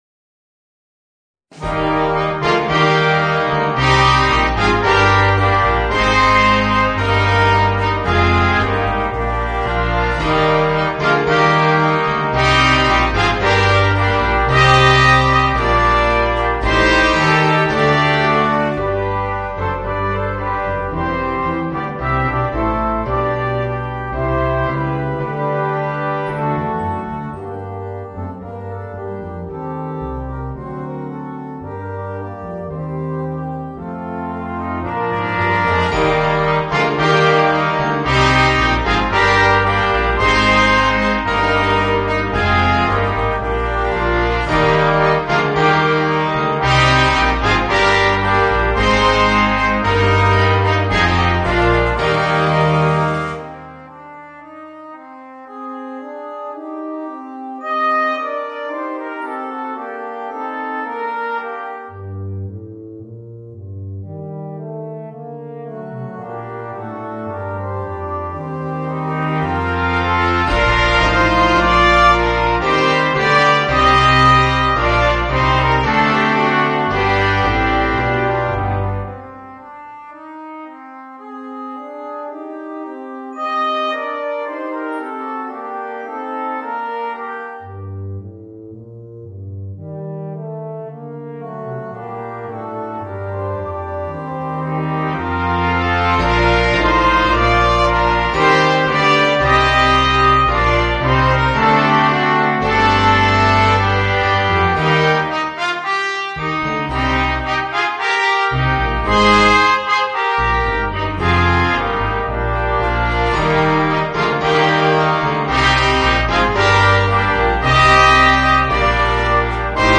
Voicing: 2 Trumpets, Trombone, Euphonium and Tuba